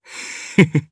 DarkKasel-Vox_Happy2_jp.wav